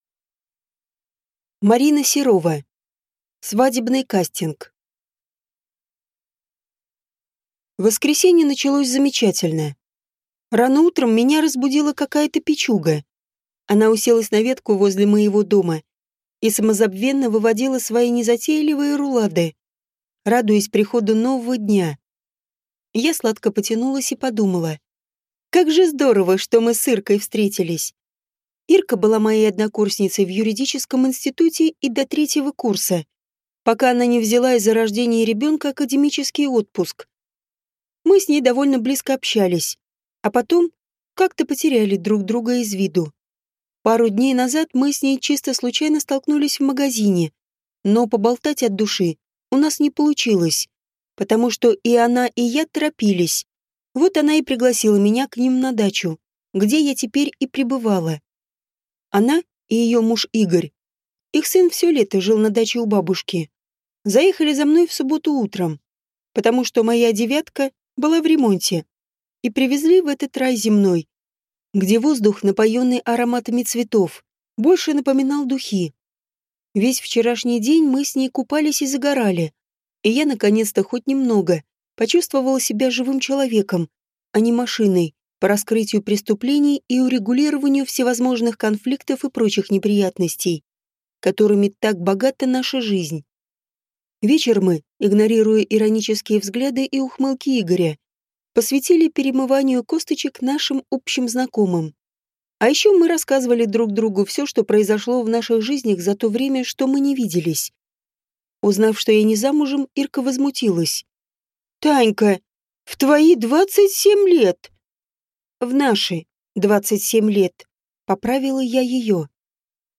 Аудиокнига Свадебный кастинг | Библиотека аудиокниг